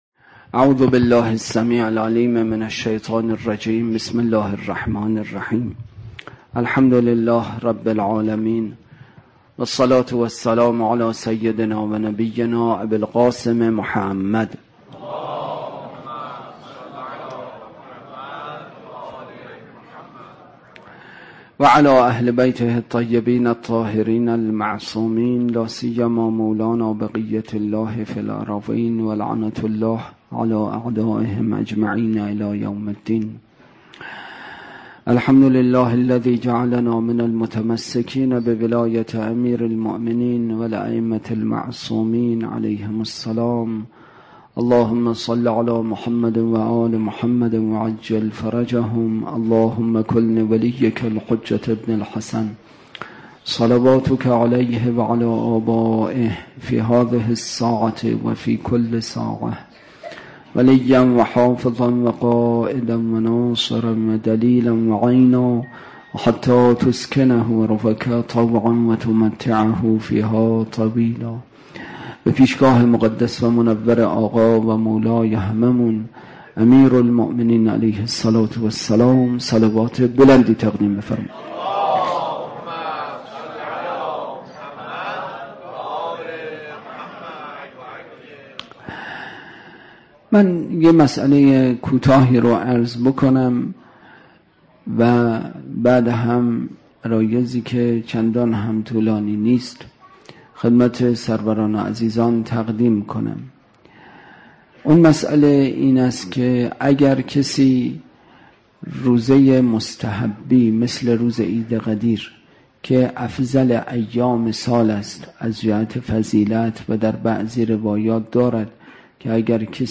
عید غدیر